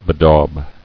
[be·daub]